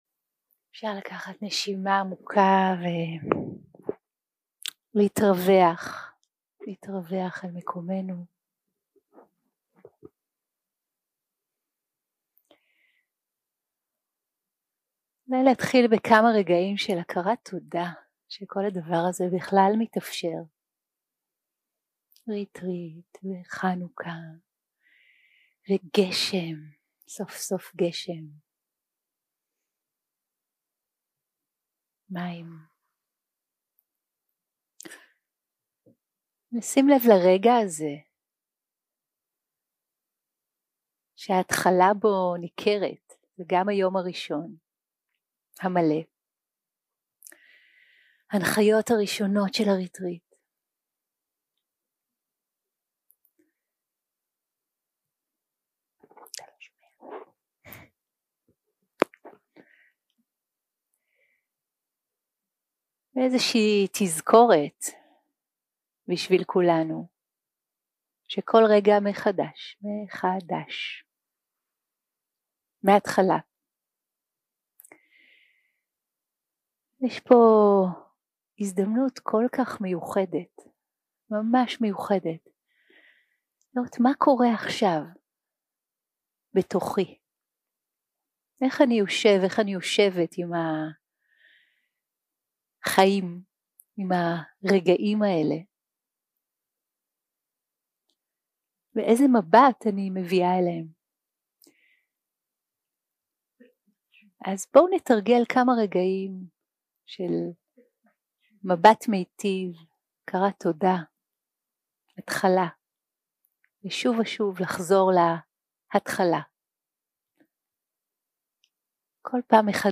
יום 2 - הקלטה 1 - בוקר - הנחיות למדיטציה - לשמוט את הסיפורים ולהתחבר לגוף 0:00 0:00 סוג ההקלטה: סוג ההקלטה: שיחת הנחיות למדיטציה שפת ההקלטה: שפת ההקלטה: עברית